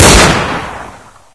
g3_fire1.ogg